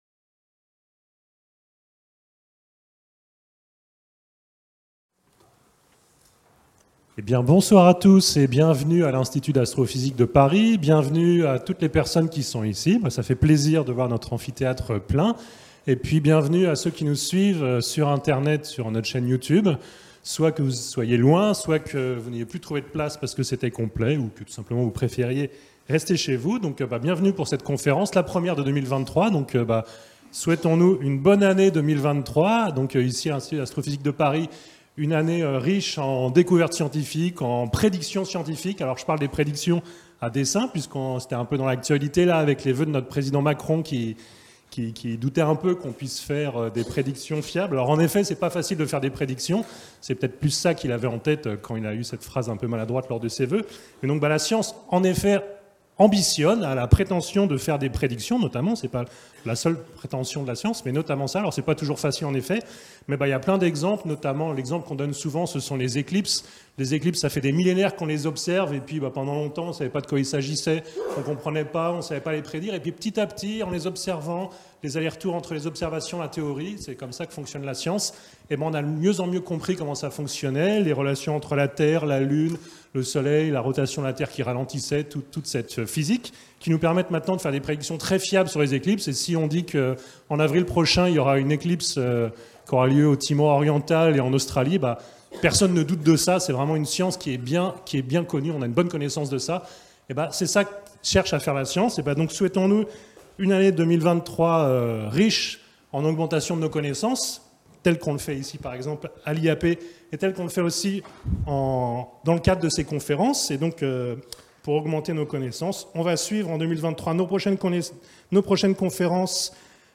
Ces observations nous permettent d'étudier des objets astrophysiques jusqu'ici invisibles, et ont déjà donné lieu à de surprenantes découvertes. Dans cet exposé, nous reviendrons sur les bases de la relativité générale afin de donner une description intuitive du phénomène d'ondes gravitationnelles.